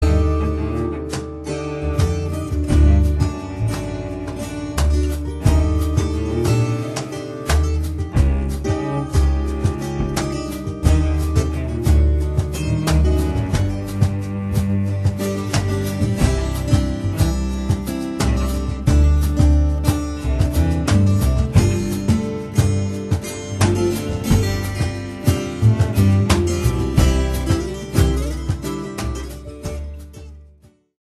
tango caboclo